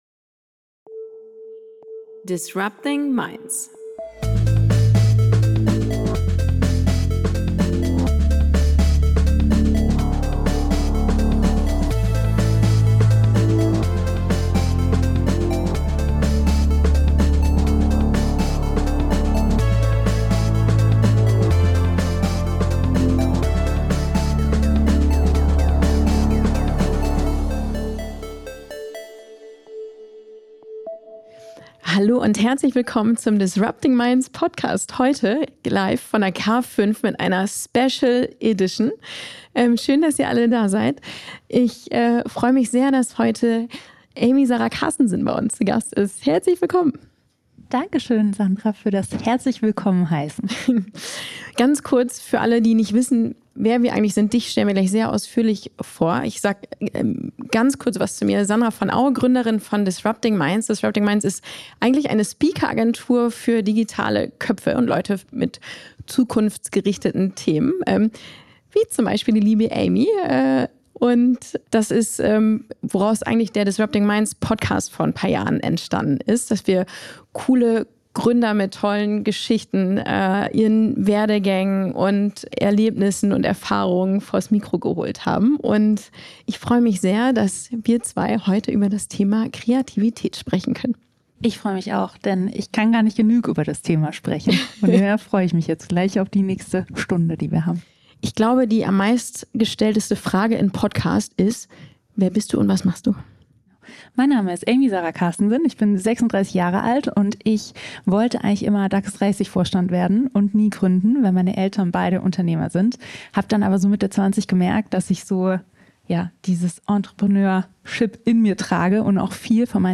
Diese Folge kommt direkt von der Bühne der K5!